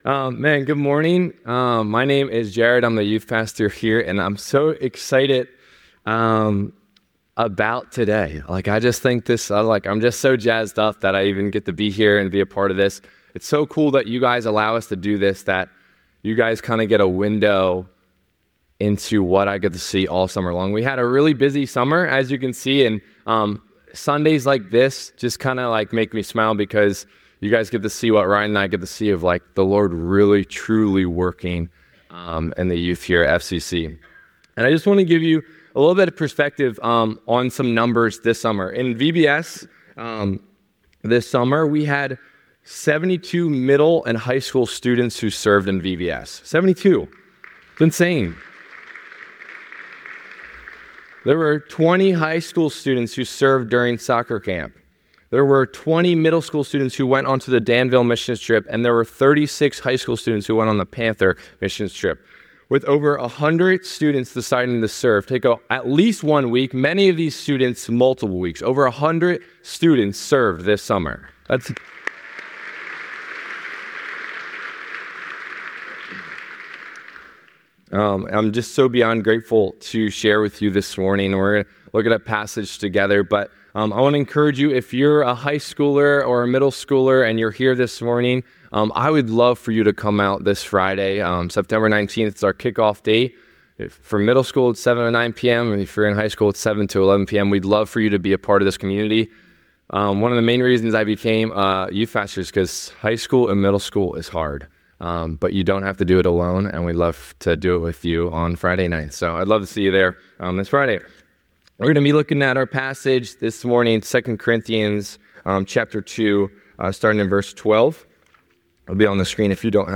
The sermon concludes with a prayer asking God to work in their lives, reminding them of their worth and encouraging them to embody Christ's presence in the world.